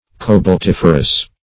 Search Result for " cobaltiferous" : The Collaborative International Dictionary of English v.0.48: Cobaltiferous \Co`balt*if"er*ous\, a. [Cobalt + -ferous.]